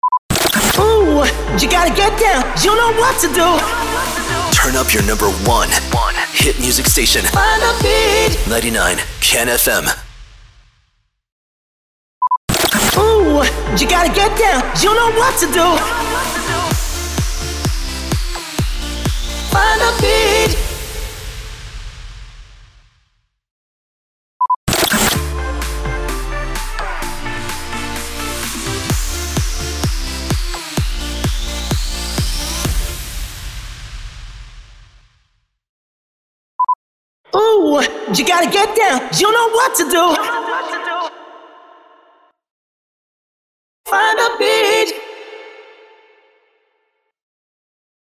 790 – SWEEPER – YOU GOTTA GET DOWN